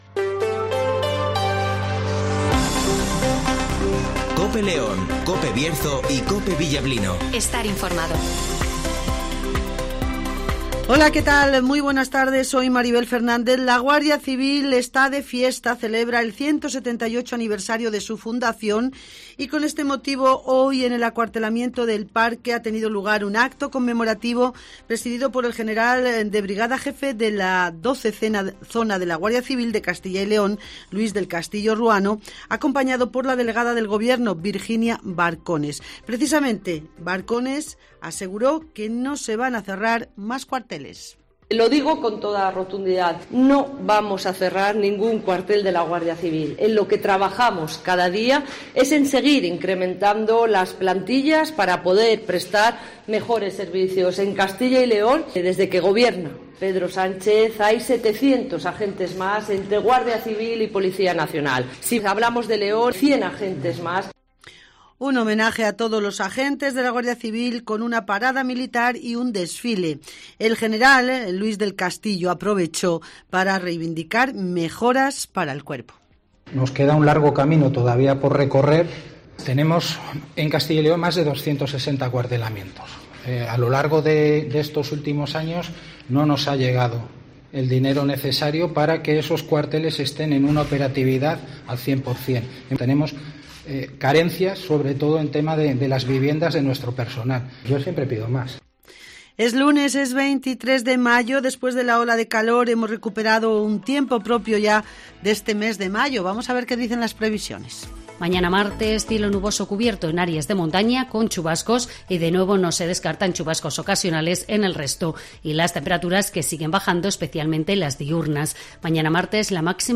95.3 FM y 1.215 OM
- Virginia Barcones ( Delegada del Gobierno en CyL )
- Olegario Ramón ( Alcalde de Ponferrada )